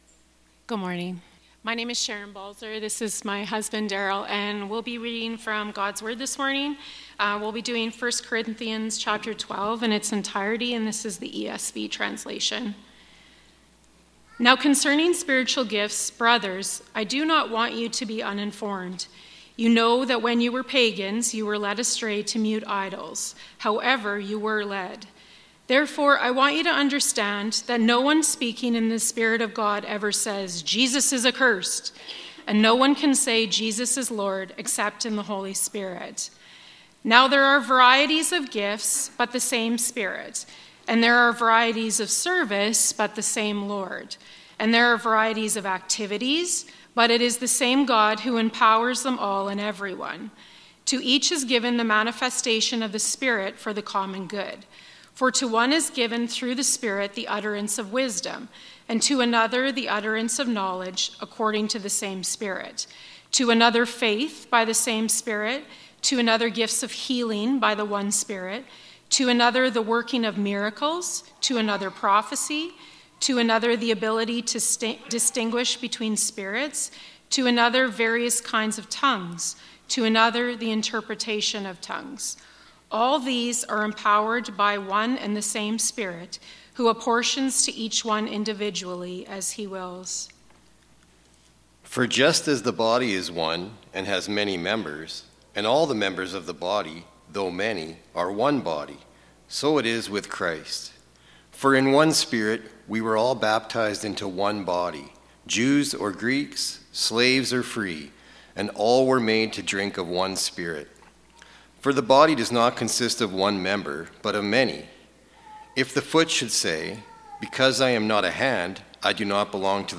Passage: 1 Corinthians 12:1-31 Service Type: Sunday Morning